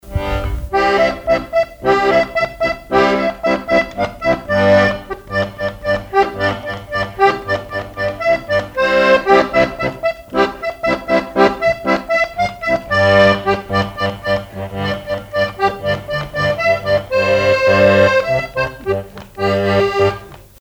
danse : quadrille
Chansons et répertoire du musicien sur accordéon chromatique
Pièce musicale inédite